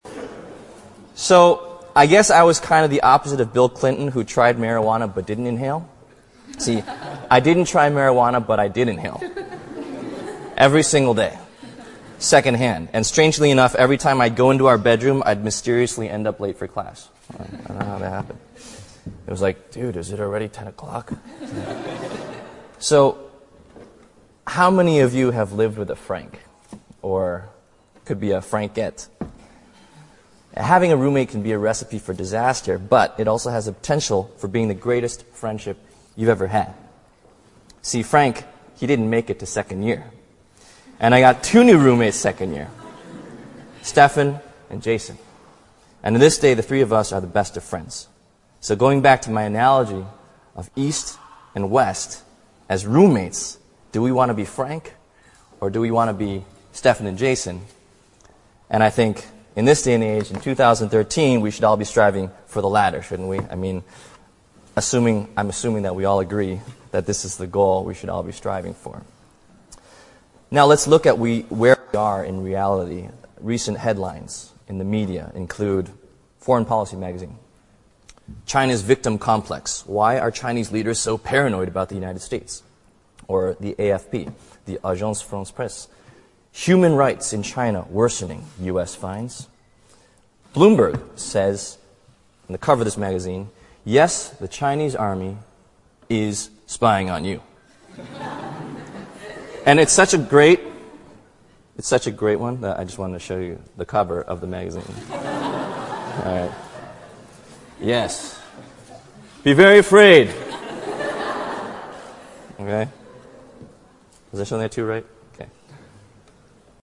王力宏牛津大学演讲 第3期 听力文件下载—在线英语听力室